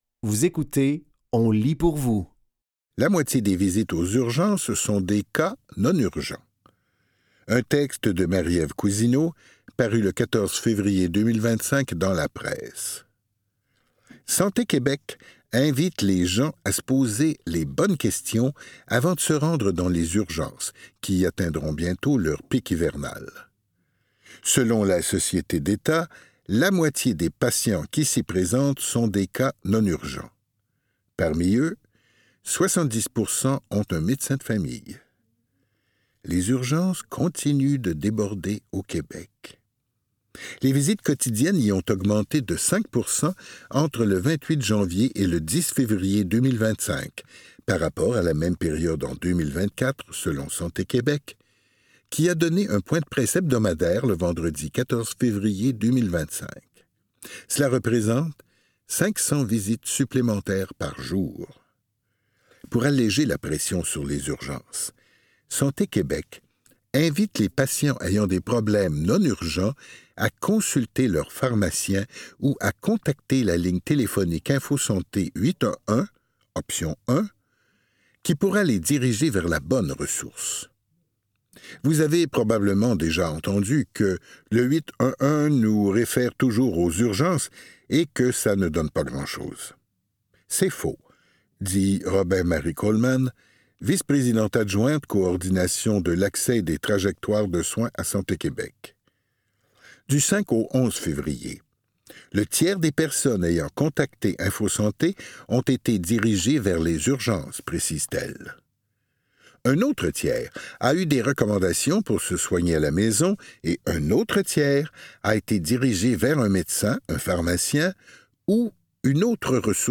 Dans cet épisode de On lit pour vous, nous vous offrons une sélection de textes tirés des médias suivants : La Presse, Le Nouvelliste, ONU Femmes et Le Devoir.